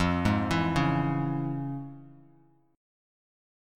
FmM7#5 chord